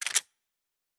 Weapon 09 Foley 2.wav